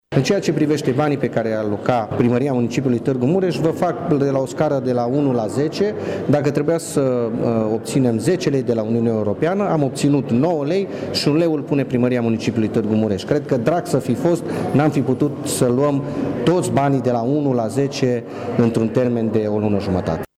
Viceprimarul Claudiu Maior a spus la finalul şedinţei că Primăria a reuşit să atragă maximum posibil din această sursă de finanţare: